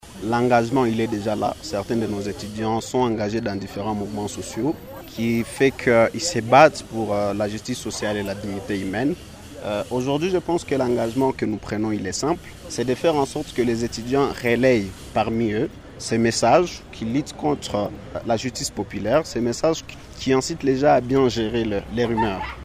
Deux thèmes à savoir la gestion des rumeurs et la justice populaire face au Droit positif congolais ont été abordés au cours d’une conférence débat organisée samedi 21 juin 2025 par Radio Maendeleo en faveur des étudiants de l’Institut Supérieur Pédagogique ISP Bukavu.